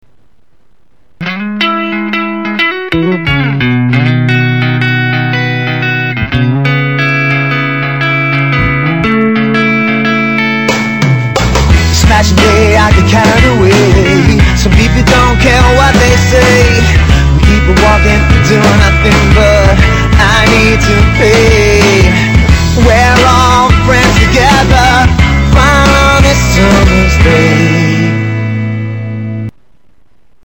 流行 R&B